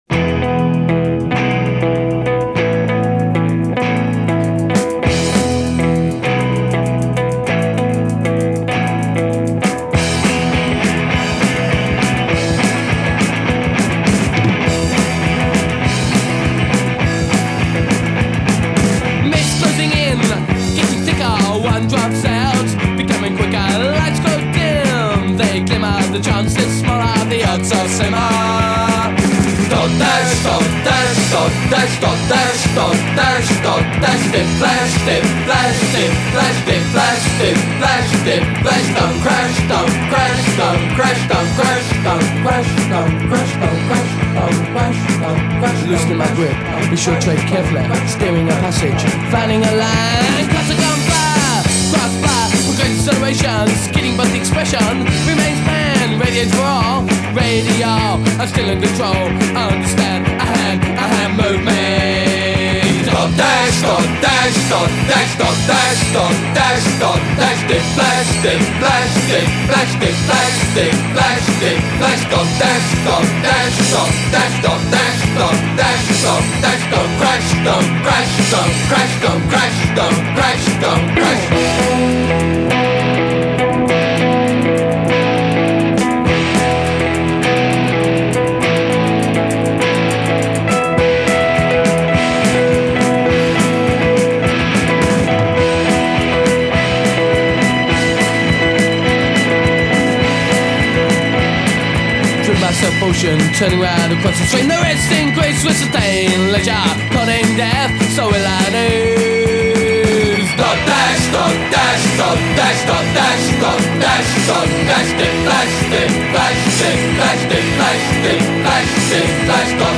Rhythm and counter-rhythm live easily beside one another.